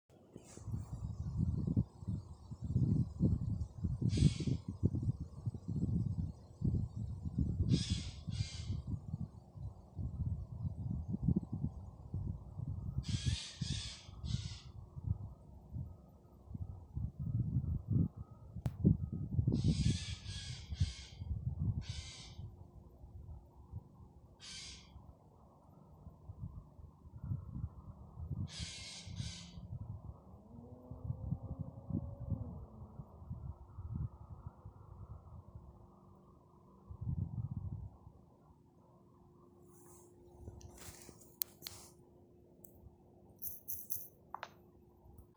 Sīlis, Garrulus glandarius
StatussUztraukuma uzvedība vai saucieni (U)